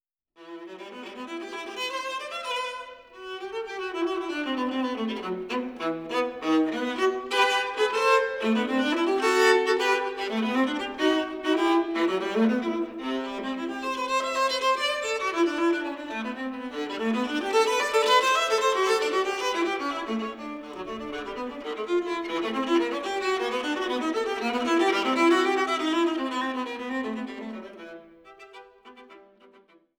im Studio der Musikhochschule aufgenommen